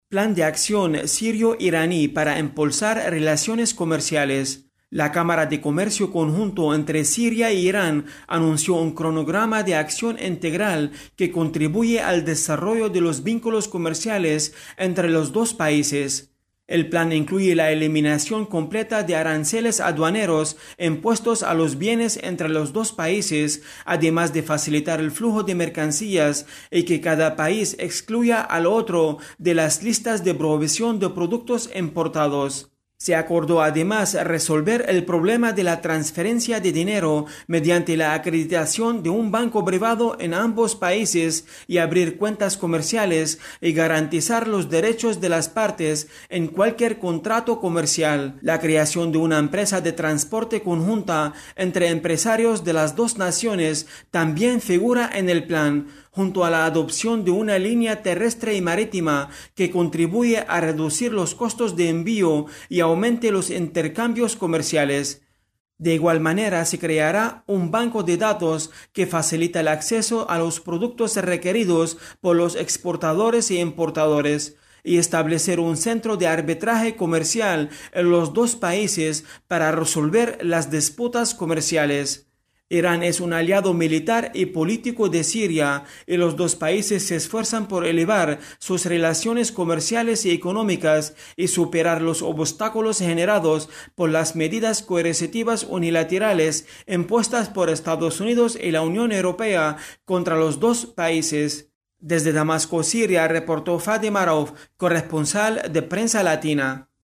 desde Damasco